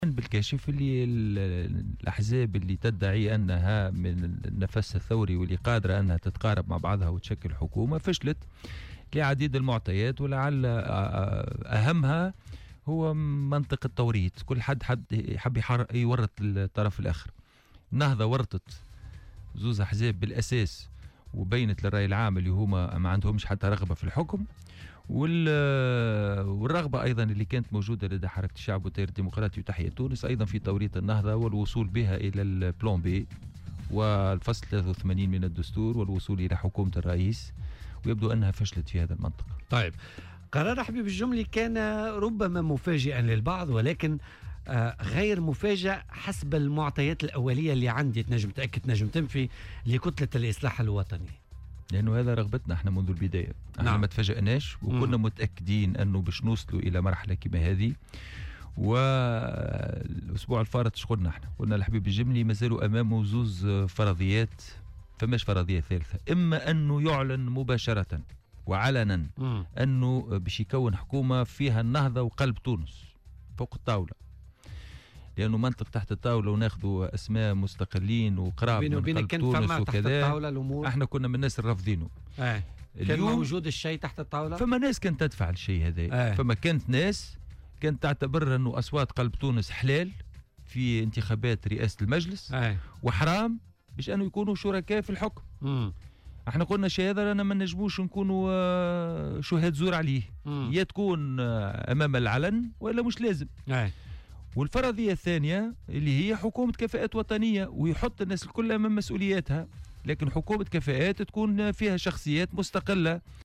وأضاف في مداخلة له اليوم في برنامج "بوليتيكا" أن "التيار" و "حركة الشعب" أرادا توريط النهضة من خلال انسحابهما من المشاورات وعدم المشاركة في الحكومة، كما أن النهضة قامت بتوريطهما أيضا بعد إعلانها عن عدم رغبتهما في المشاركة في الحكم.